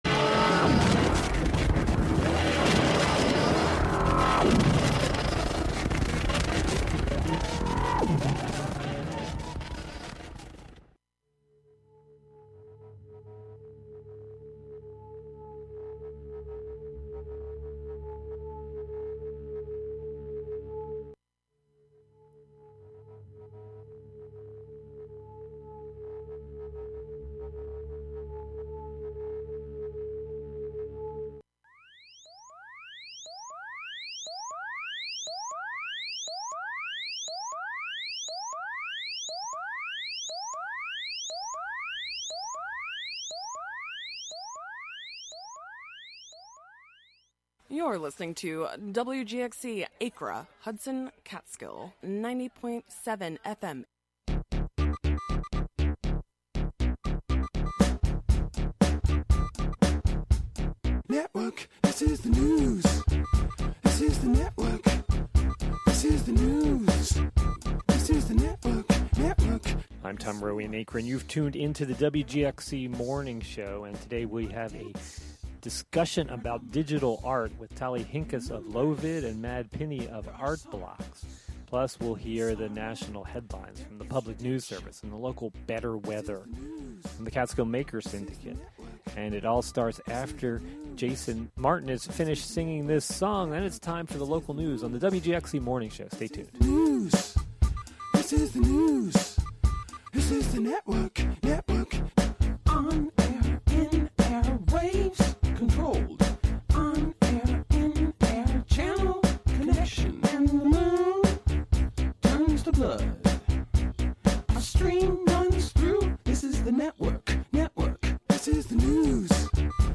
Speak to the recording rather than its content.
At 9 a.m. "The WGXC Daily," with local headlines, weather, and previews of community events, airs.